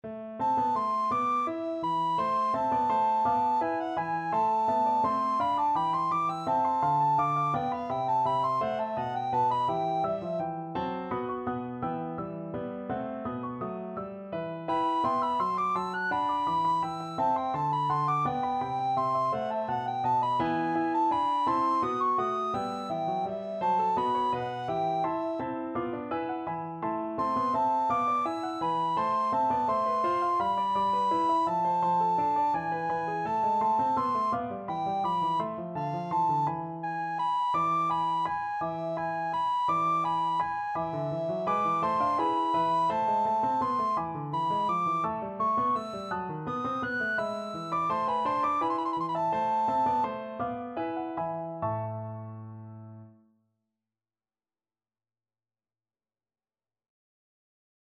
Free Sheet music for Soprano (Descant) Recorder
A minor (Sounding Pitch) (View more A minor Music for Recorder )
3/8 (View more 3/8 Music)
Classical (View more Classical Recorder Music)
vivaldi_stabat_amen_REC.mp3